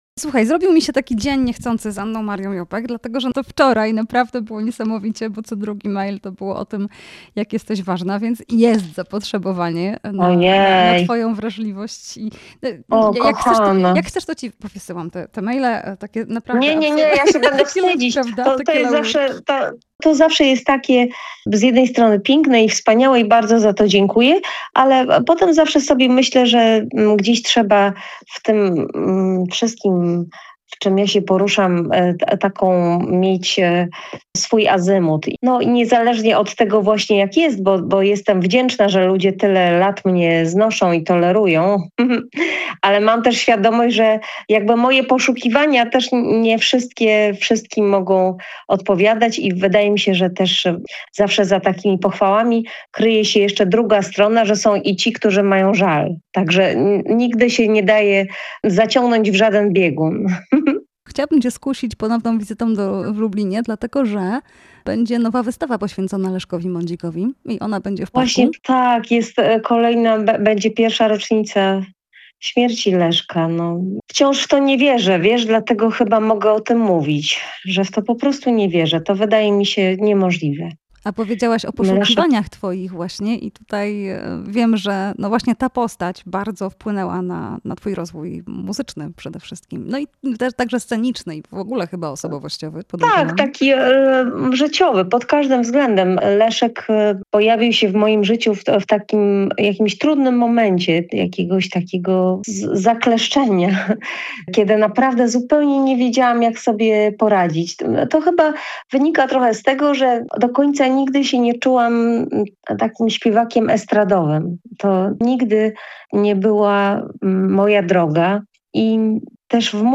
Jazzowy Dzień Kobiet: Anna Maria Jopek [POSŁUCHAJ ROZMOWY]